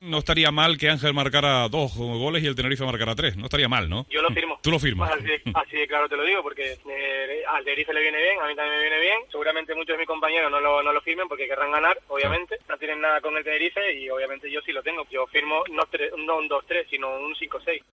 Declaraciones a la radio 'El Día de Santa Cruz de Tenerife',